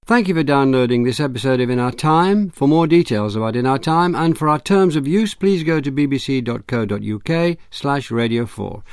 The triphthong [aʊə] is often pronounced just as /ɑː/, especially in some words like the possessive our.
Actually, this is the way our  is currently said in British English (and very often in American English too, although in AE the final r is pronounced).